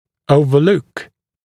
[ˌəuvə’luk][ˌоувэ’лук]не заметить, просмотреть, пропустить; не обращать внимания, не придавать значения, игнорировать